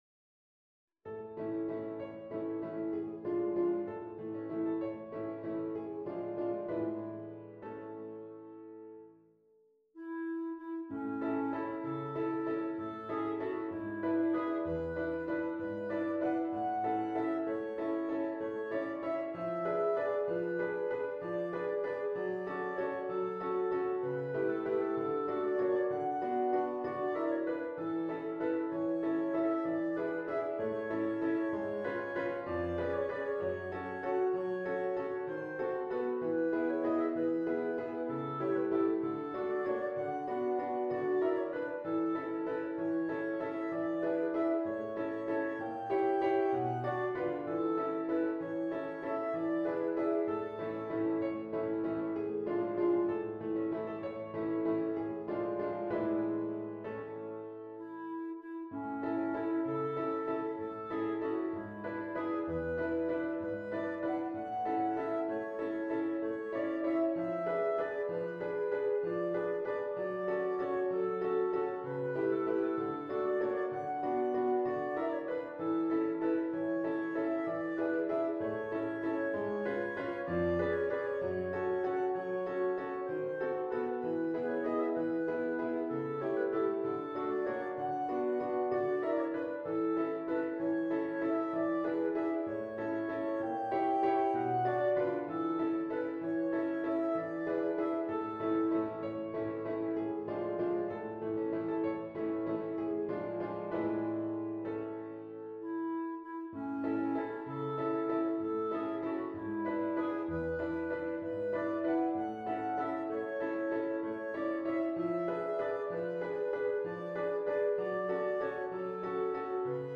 Bb clarinet